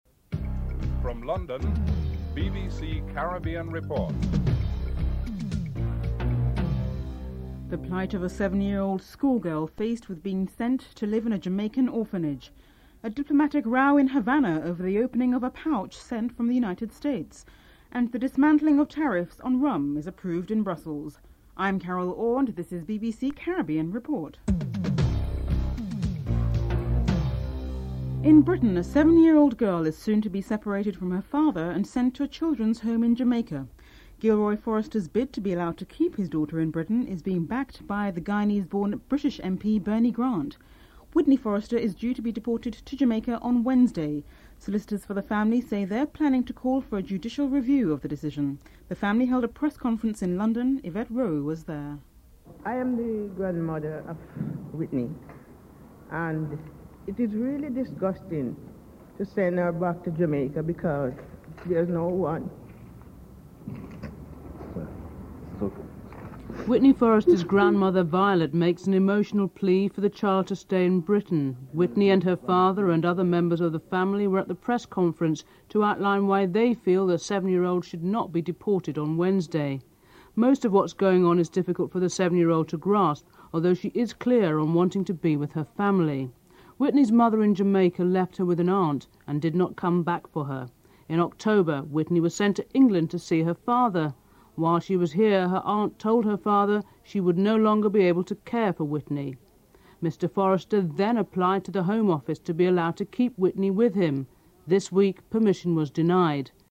The British Broadcasting Corporation
Wyclef Jean is interviewed (11:25-13:18)